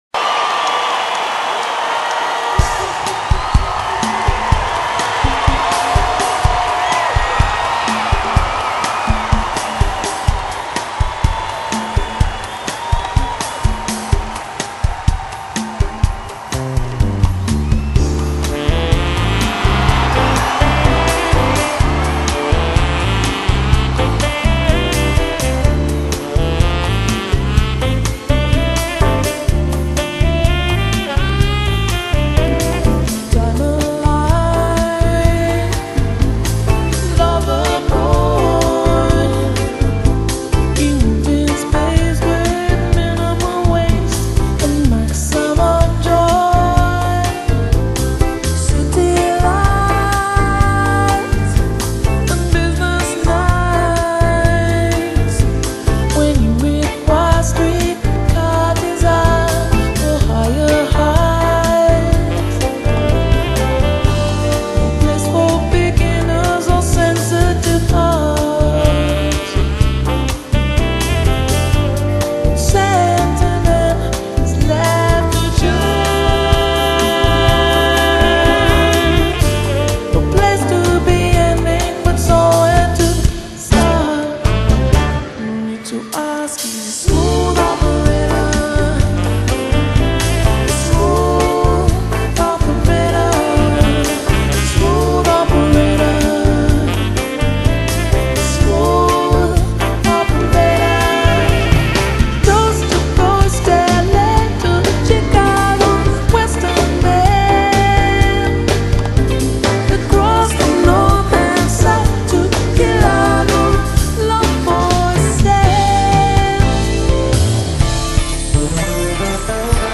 Pop/Soul/Soft Jazz/Funk
演唱会就只有她和她的乐队
目眩神迷气氛弥漫，悠扬细致的旋音与你同声气息